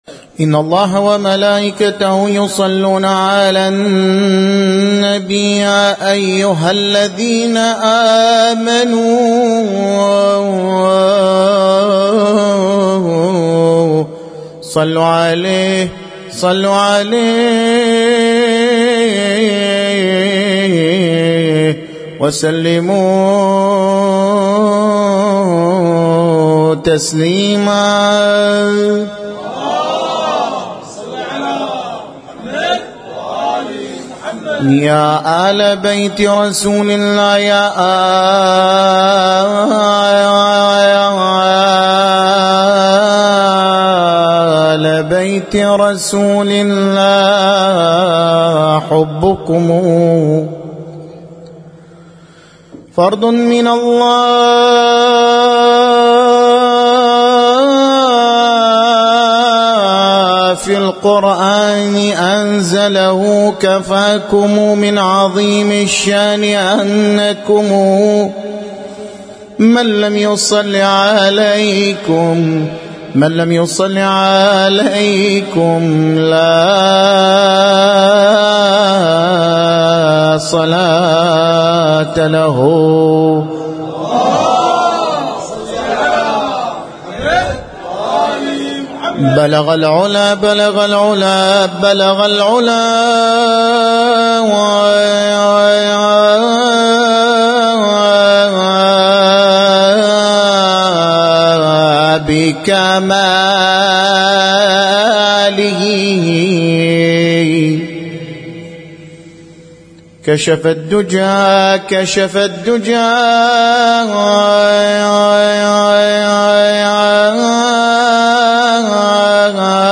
مدائح مولد الرسول الأعظم سماحة - ليلة (13) من شهر ربيع الأول1445